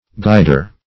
guider - definition of guider - synonyms, pronunciation, spelling from Free Dictionary Search Result for " guider" : The Collaborative International Dictionary of English v.0.48: Guider \Guid"er\, n. A guide; a director.